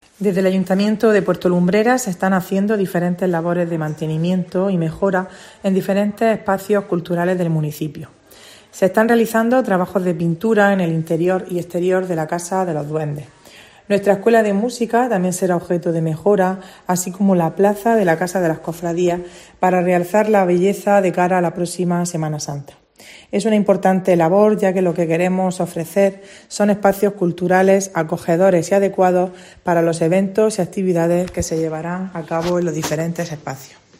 Toñi Navarro, concejal de Puerto Lumbreras